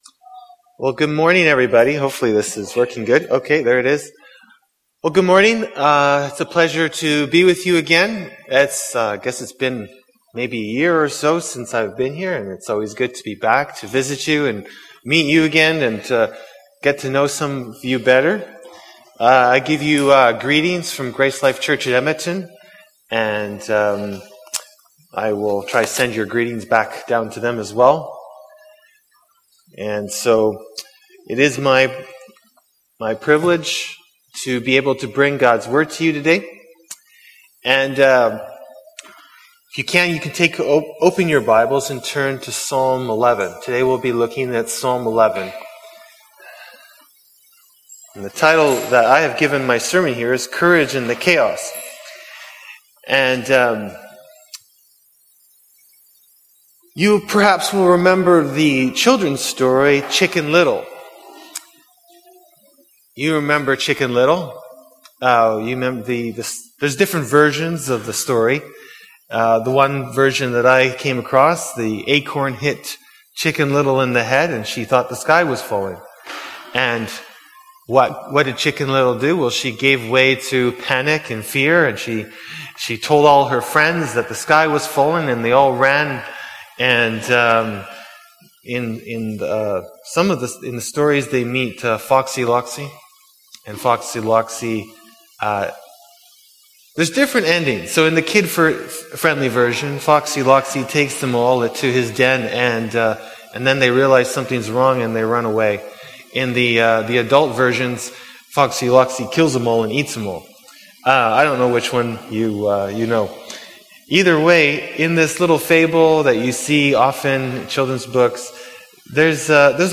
Category: Sermon